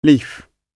leaf-gb.mp3